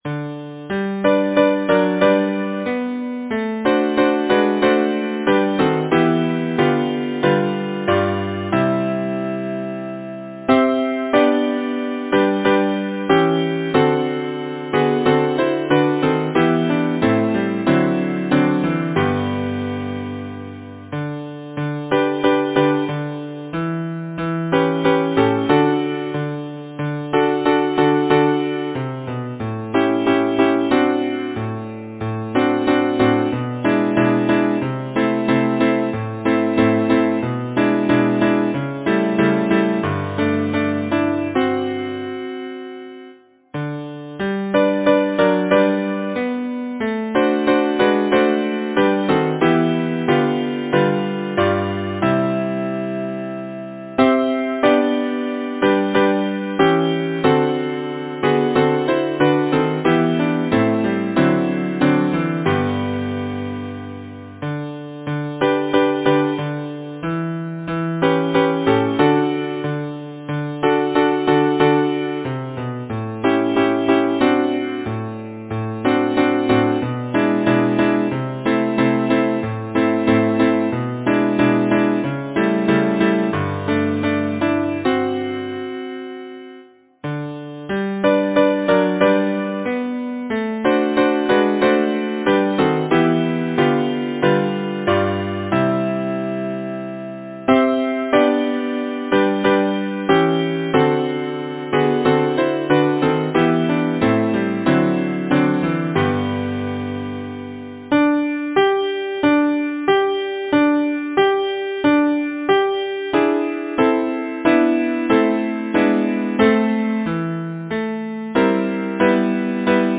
Title: Mary had a little lamb Composer: Clarence T. Steele Lyricist: Sarah Josepha Buell Hale Number of voices: 4vv Voicing: SATB Genre: Secular, Partsong, Nursery rhyme, Humorous song
Language: English Instruments: A cappella